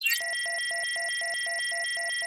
countdown_converted.wav